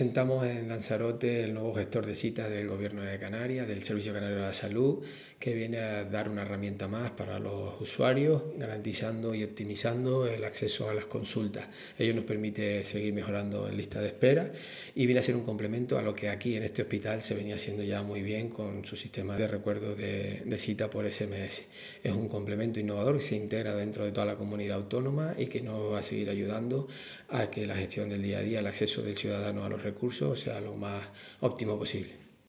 Presentación en el Hospital Universitario Doctor José Molina Orosa con el director del SCS, Adasat Goya, y el gerente insular, Pablo Eguia:
El-director-del-SCS-durante-la-presentacion.mp3